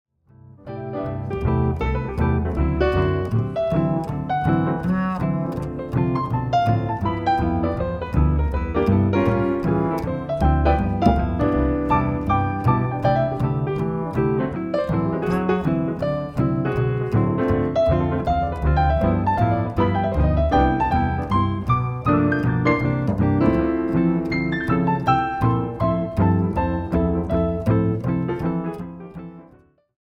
A play-along track in the style of Jazz.